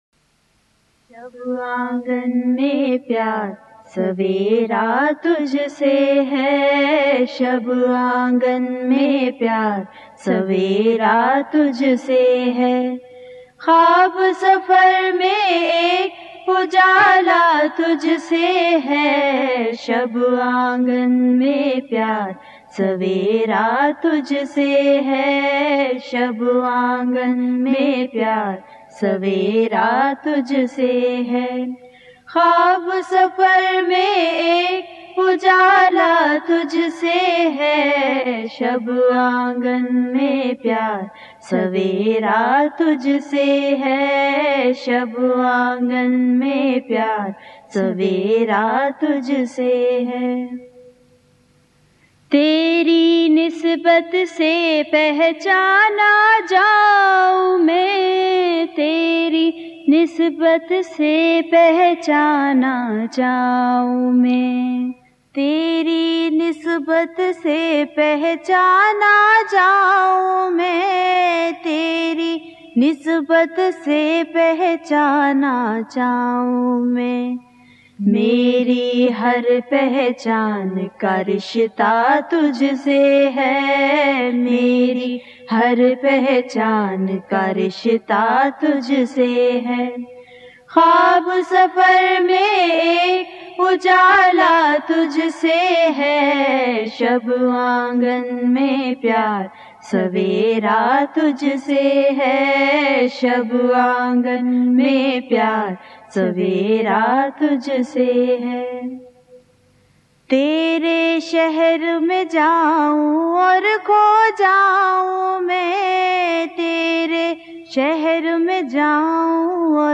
Naats
Khatoon Naat Khwan